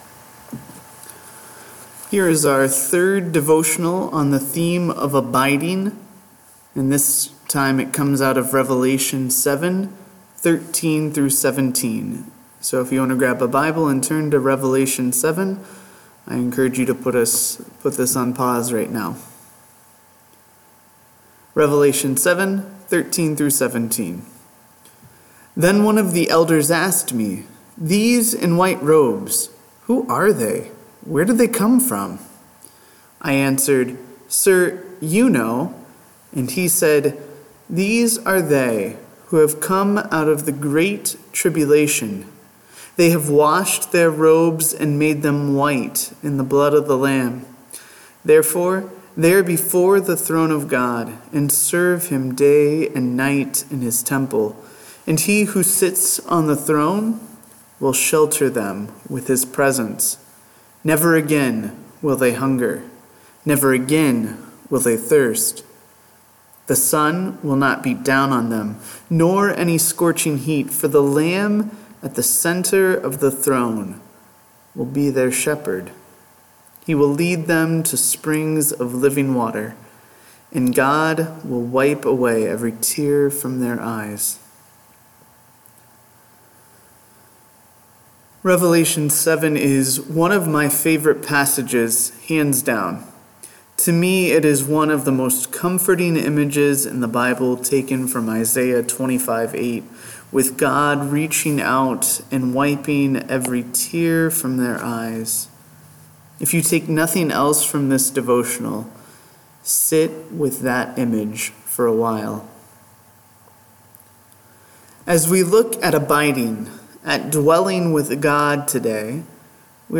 Sheltered by the Throne – Devotional